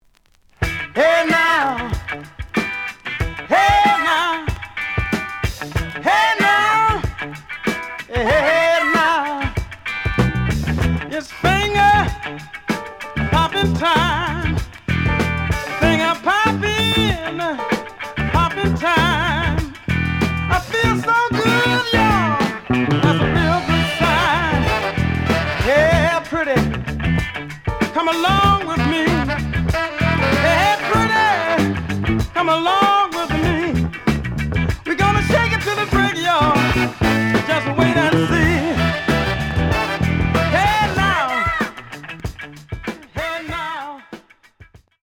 The audio sample is recorded from the actual item.
●Genre: Funk, 70's Funk
Slight damage on both side labels. Plays good.)